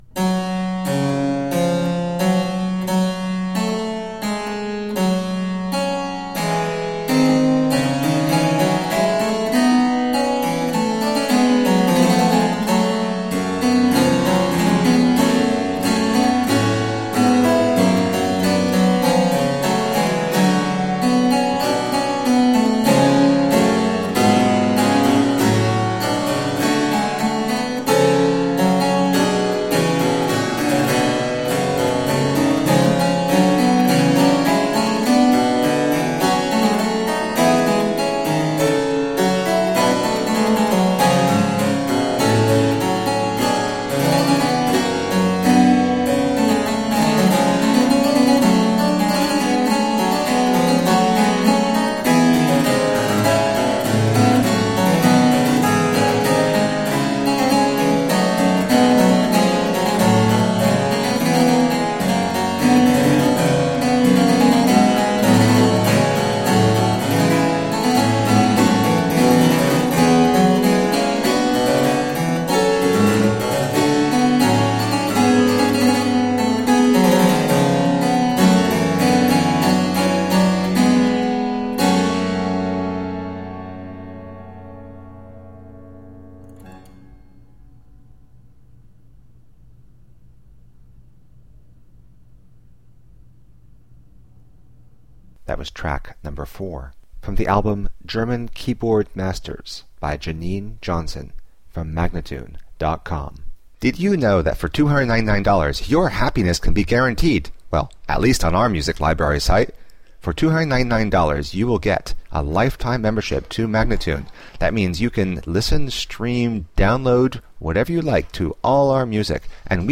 Harpsichord and fortepiano classics.